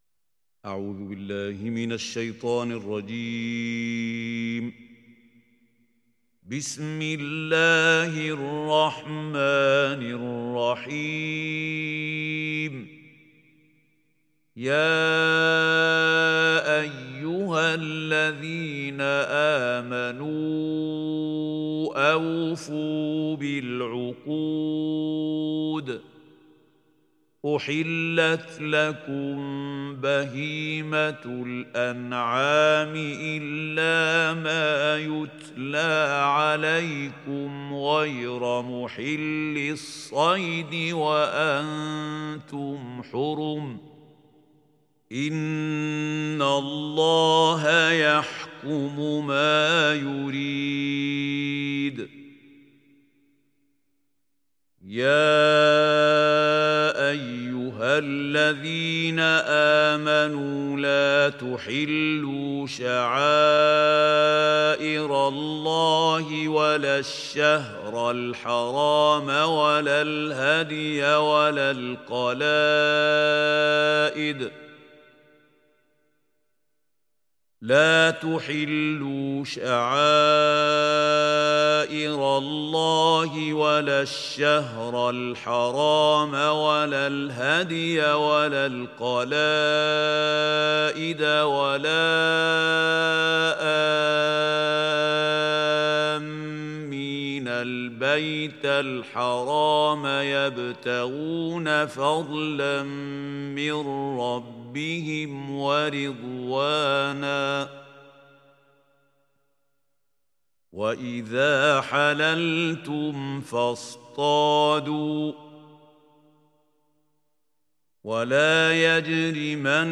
Maide Suresi İndir mp3 Mahmoud Khalil Al Hussary Riwayat Hafs an Asim, Kurani indirin ve mp3 tam doğrudan bağlantılar dinle